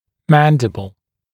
[‘mændɪbl][‘мэндибл]нижняя челюсть